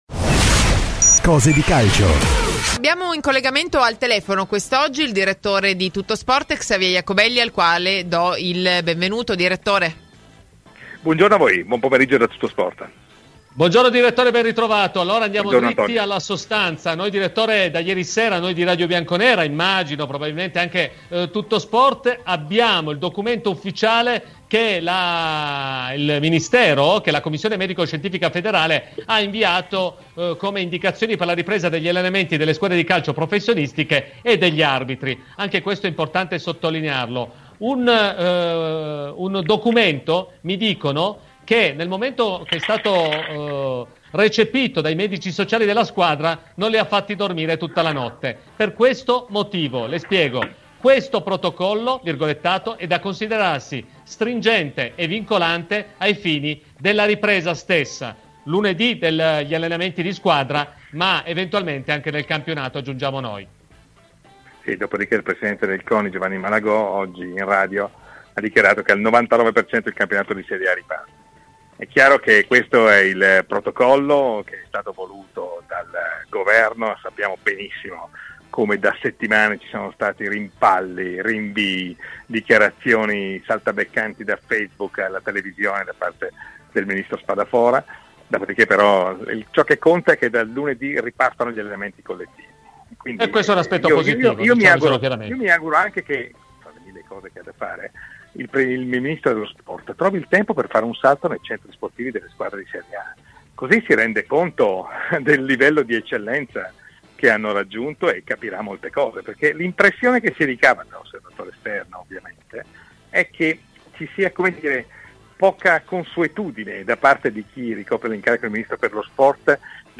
Ai microfoni di Radio Bianconera, nel corso di ‘Cose di Calcio’